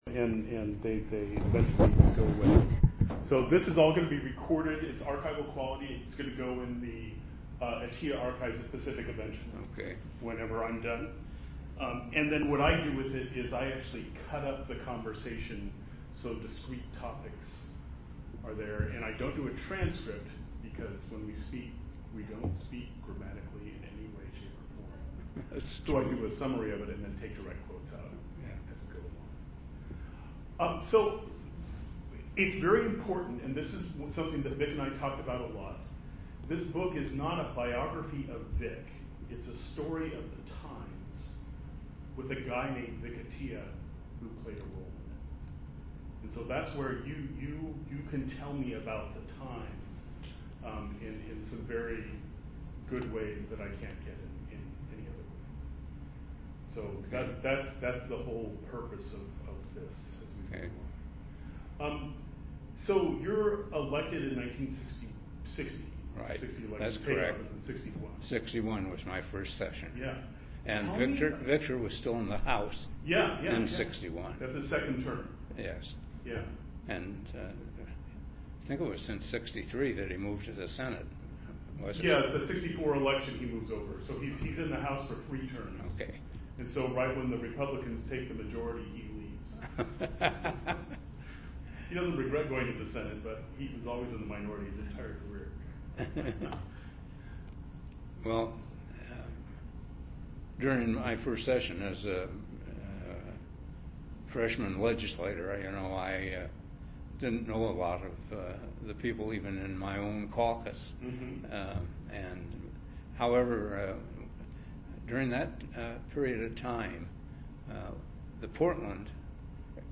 f09cf7b8cb1fd35b87d4f9040a2e95af2bfa4146.mp3 Title Phil Lang interview on Atiyeh Description An interview of Phil Lang (1929-2024) regarding Oregon's Governor Victor Atiyeh, recorded on Aug. 3, 2015. Lang served as a Democrat in Oregon's House of Representatives from 1961-1979, and was Speaker of the House from 1975-1978.